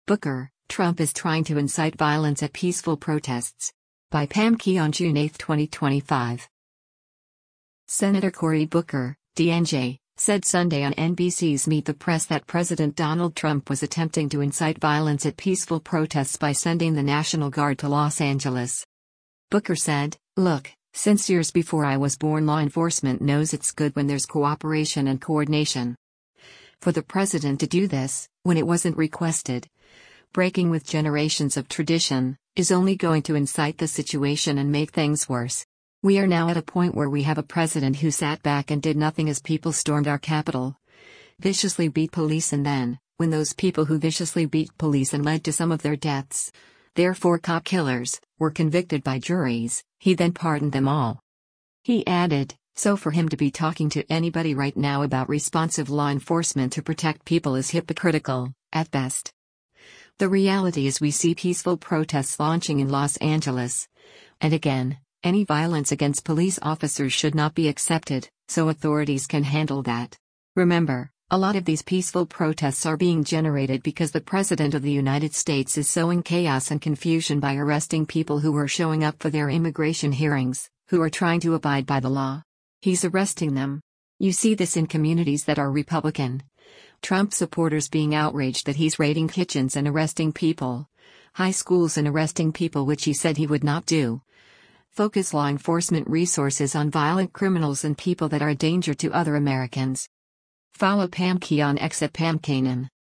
Senator Cory Booker (D-NJ) said Sunday on NBC’s “Meet the Press” that President Donald Trump was attempting to “incite” violence at “peaceful protests” by sending the National Guard to Los Angeles.